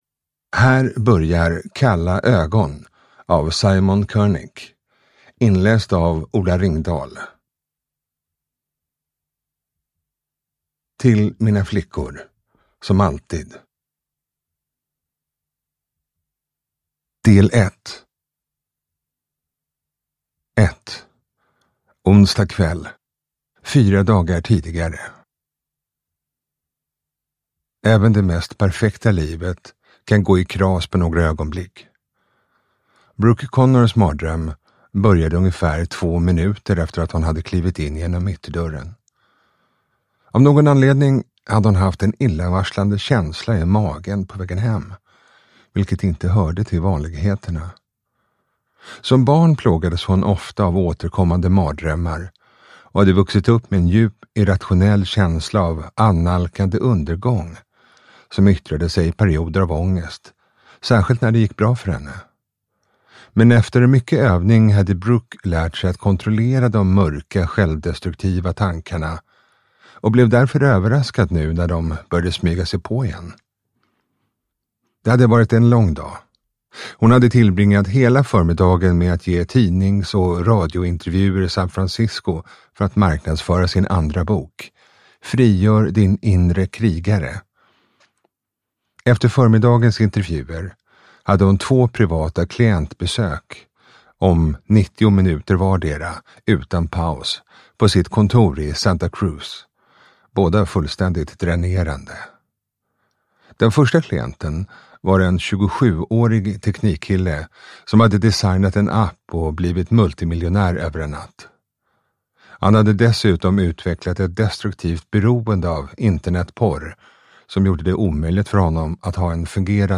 Kalla ögon – Ljudbok